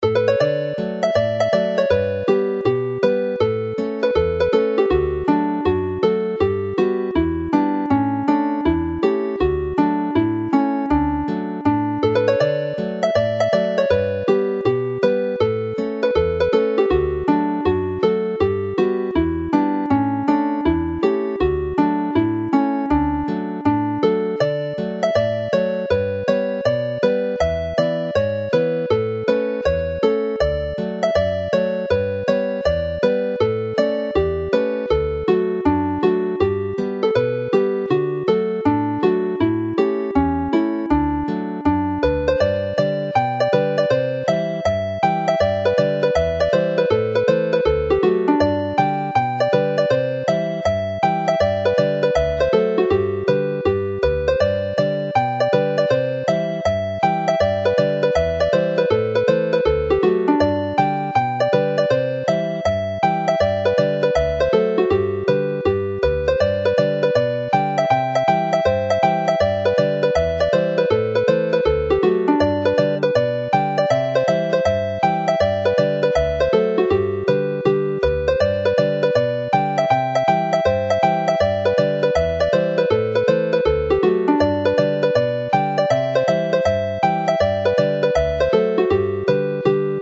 This is a set of hornpipes. Hornpipes have a skip in them,  being played almost dotted although they are normally written in straight 4-beat time.
it is played as a hornpipe.